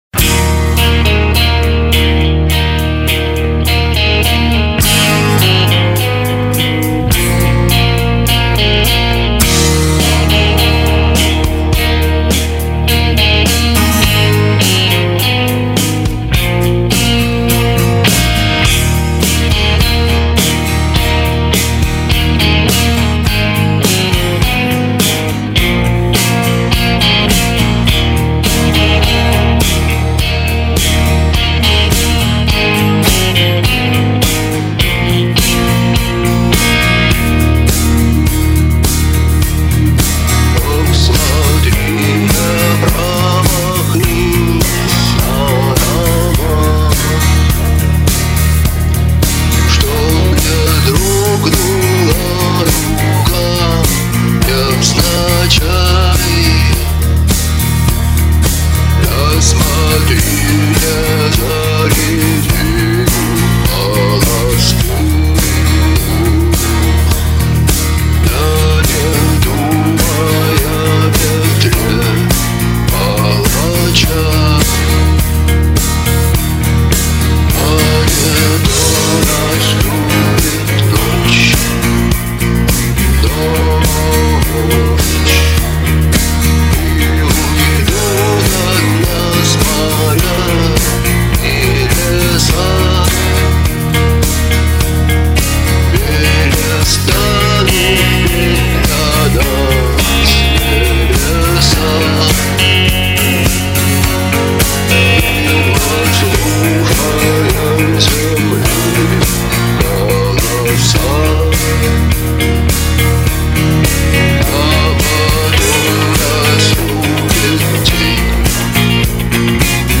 Подчищенный вариант